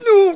dead.mp3